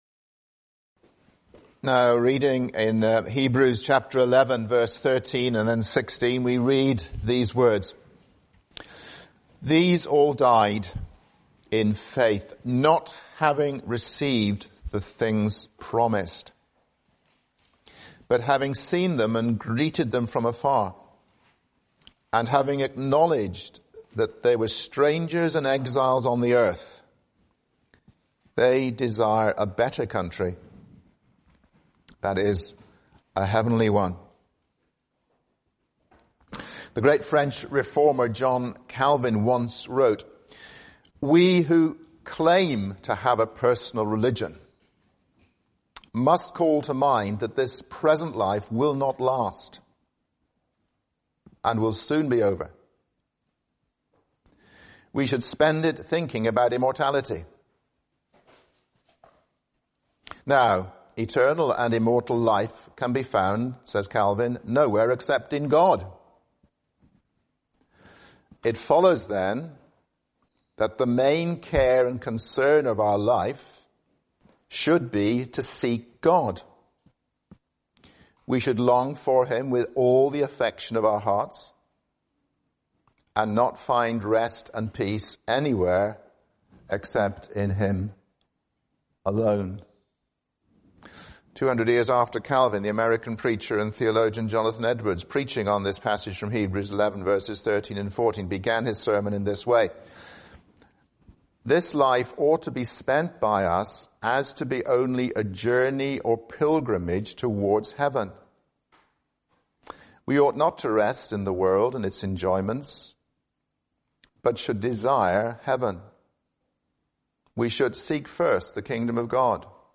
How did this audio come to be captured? Morning Service